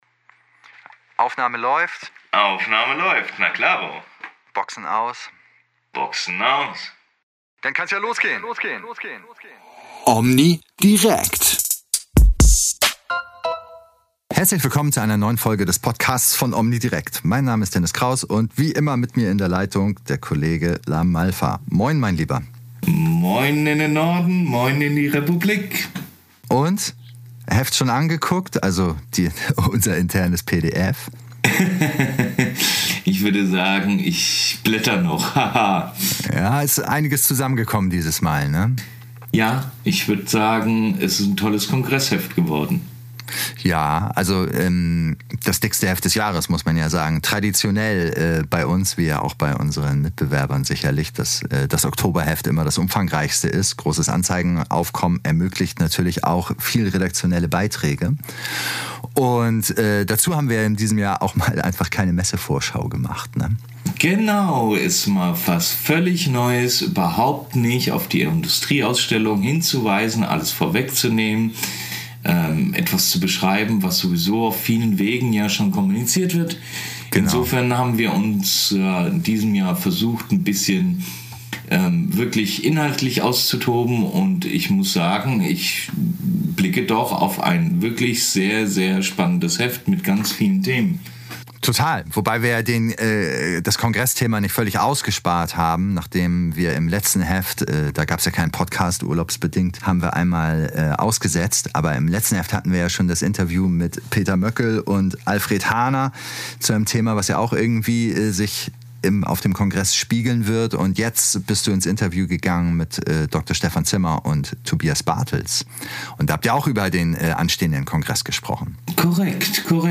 In einem kurzweiligen Gespräch reden die beiden nicht nur über einige Themen der aktuellen Ausgabe. Sie sprechen auch über ihre Erwartungen an den bevorstehenden Internationalen Hörakustiker-Kongress in Nürnberg, bei dem es sowohl thematisch als auch organisatorisch einige Neuheiten geben wird.